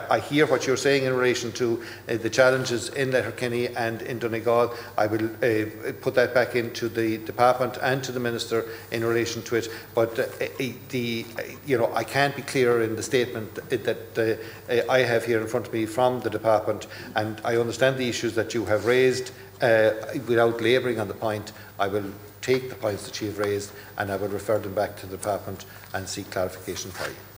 Responding, Minister of State for the Department of Education and Youth, Michael Moynihan, was unable to clarify if this was the case: